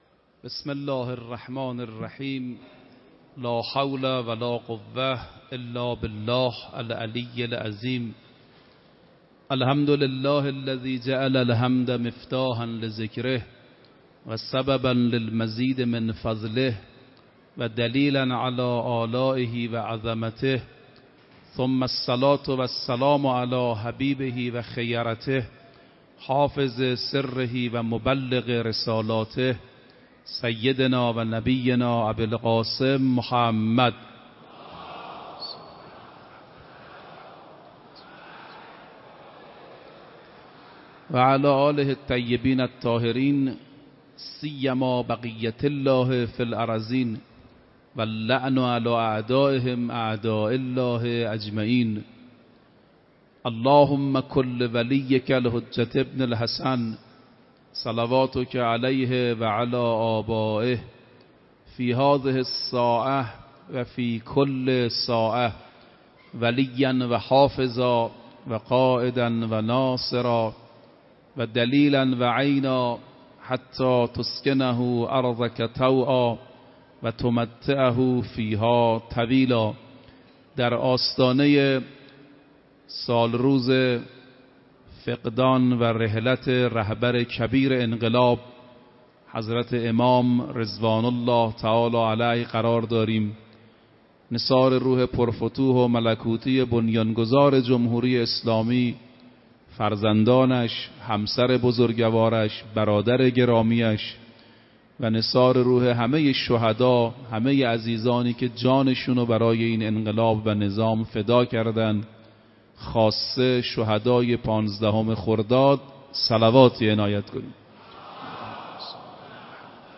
روز هفتم رمضان 96 - حرم حضرت معصومه - سخنرانی